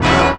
JAZZ STAB 23.wav